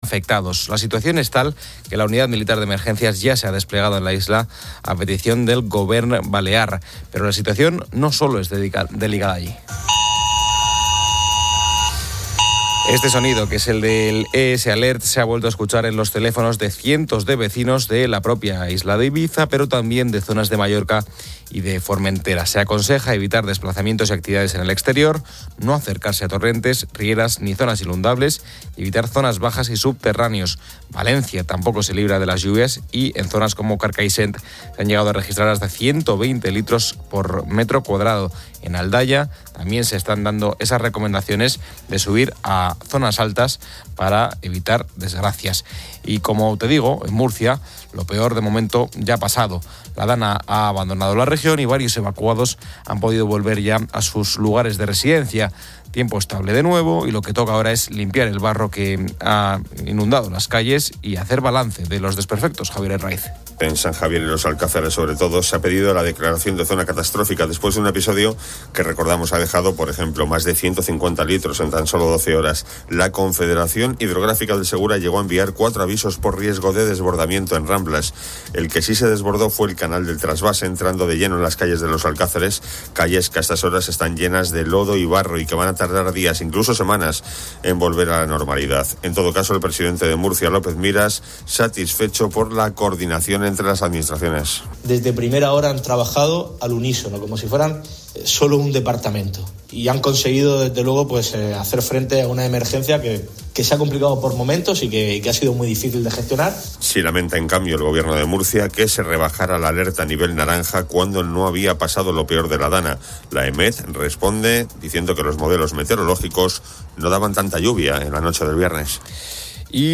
El programa 'La Noche con el Grupo Risa' es presentado por una voz de IA que elogia su liderazgo en la radio de madrugada. Posteriormente, Luján Argüelles narra su trayectoria.